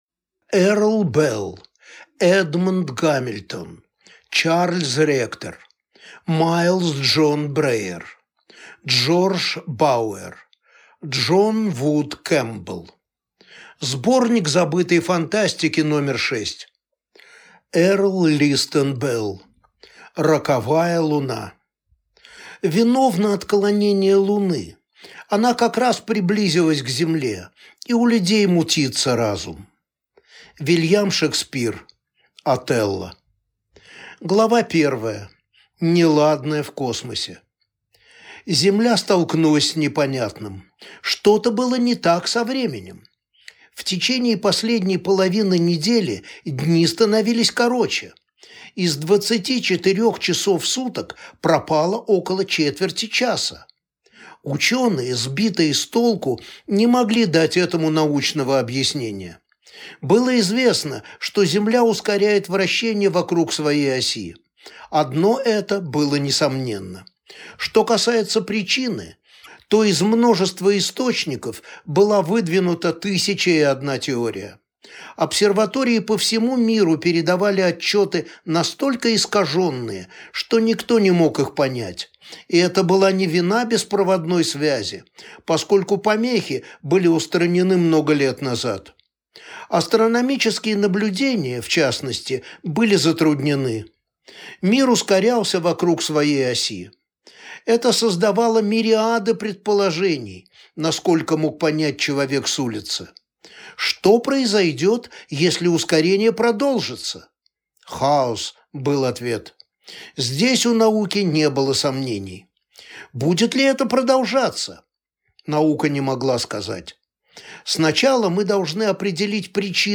Аудиокнига Сборник Забытой Фантастики №6 | Библиотека аудиокниг